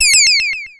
ihob/Assets/Extensions/RetroGamesSoundFX/Various/Various05.wav at master